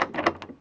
doorLocked.wav